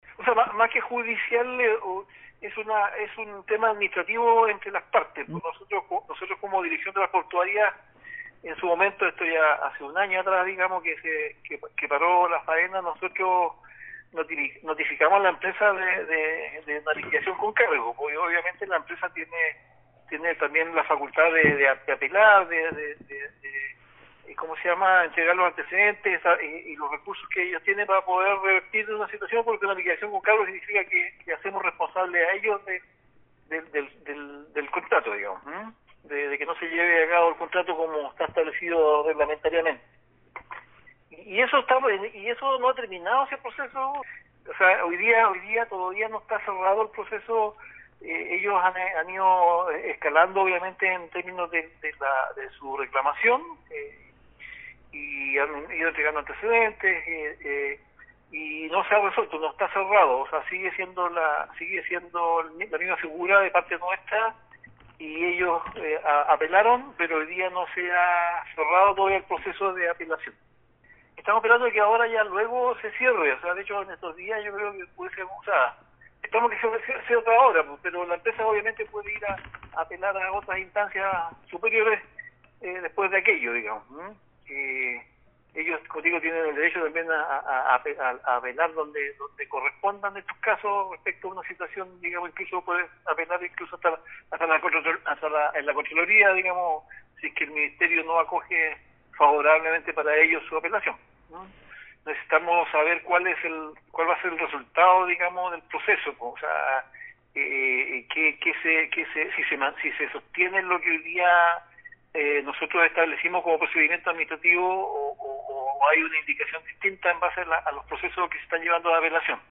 Antecedentes acerca del término hace un año de las faenas por parte de la empresa SICOMAC entregó el director provincial de Obras Portuarias, Patricio Manzanares, señalando que el caso se mantiene en la vía judicial.